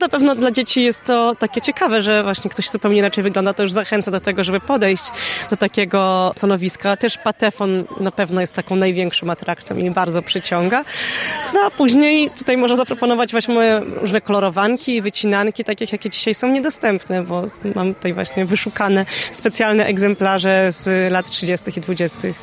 W niedzielne popołudnie (05.05.2024 r.) w Dworze Lutosławskich – Muzeum Przyrody w Drozdowie odbył się Piknik Patriotyczny z udziałem dzieci i ich rodzin w ramach Uroczystości Rocznicy Uchwalenia Konstytucji 3 Maja.